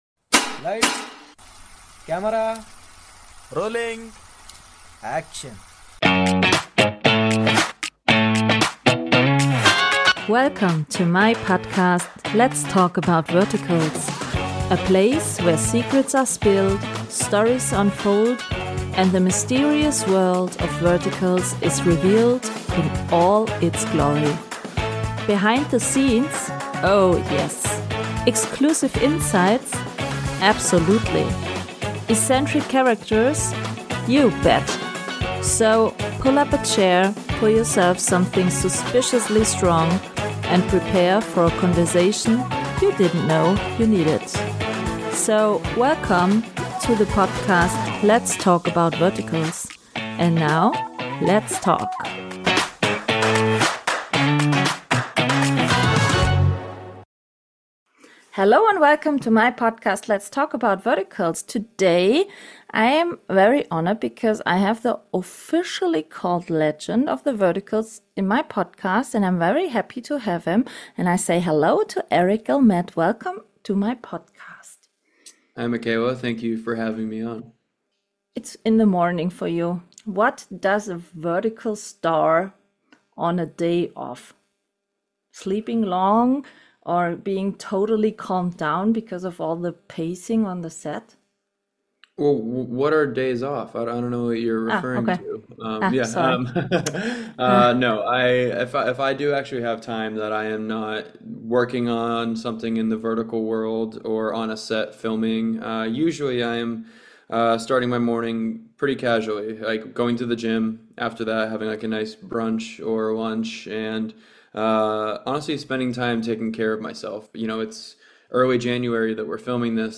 A wonderful conversation you absolutely shouldn’t miss!